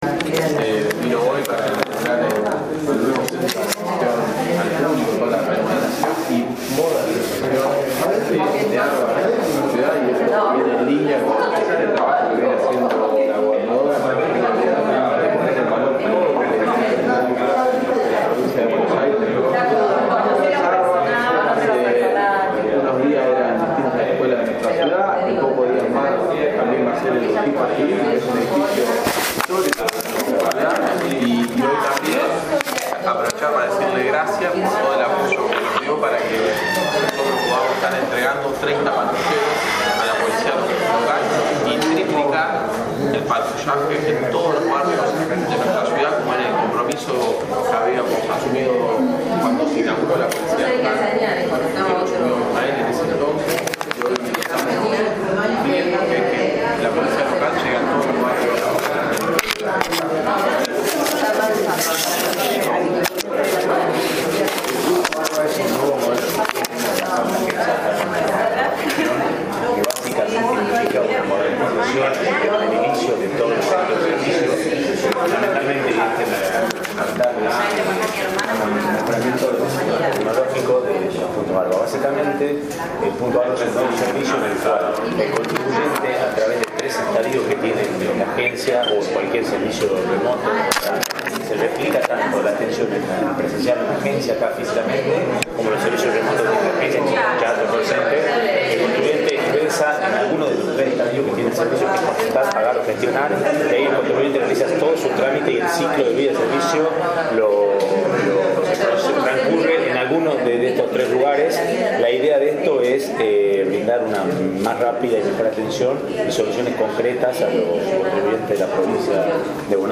En tiempo récord se remodeló y actualizó siberneticamente la agencia ARBA de San Nicolás. Se le dio apertura en forma oficial en una modesta ceremonia.